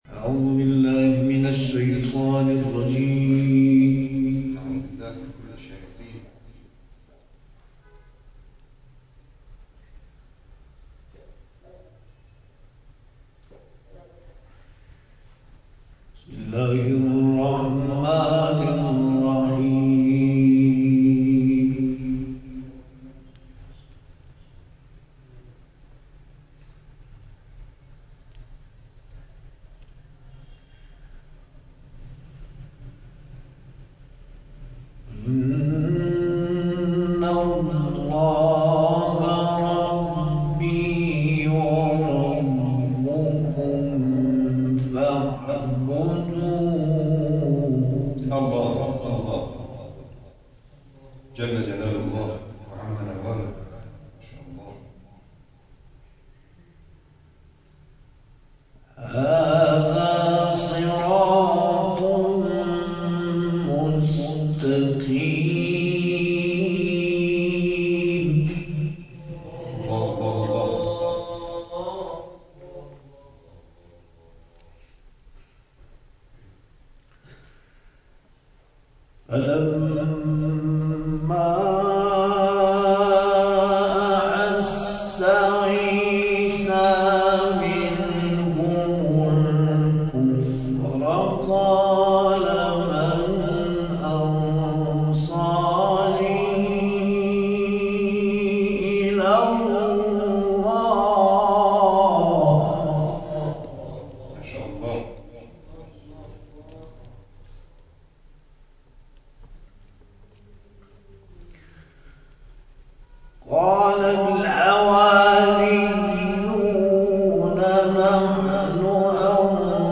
جدیدترین تلاوت
حسن ختام این جلسه قرآنی